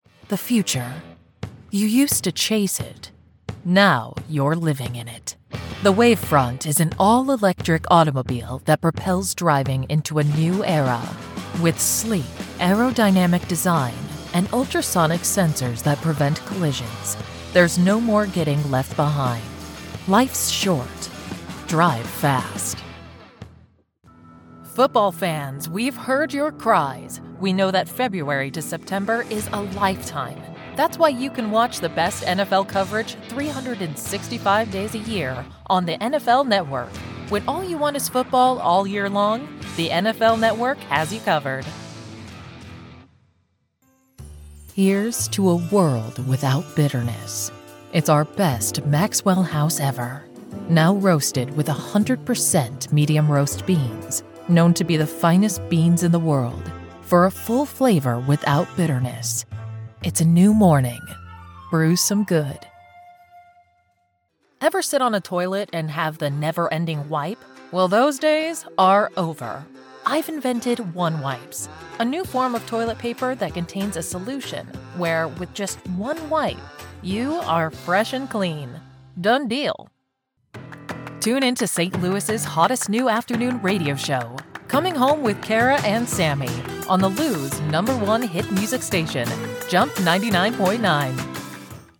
Démo commerciale
Qualités vocales : Clair, confiant, professionnel, conversationnel, chaleureux, réel, engageant, polyvalent, courageux, informatif, luxuriant, fort, puissant, sexy, profond, adaptable, personnages, autoritaire, doux, raffiné, apaisant, amical, commercial, humain, relatable, confiant,
ProfondBasMezzo-soprano
ChaleureuxDe la conversationSombreAutoritaireApaisanteSensuelVeloutéMalInformatifGraveleuxEngageantFortLuxuriantLisseBrillantReliable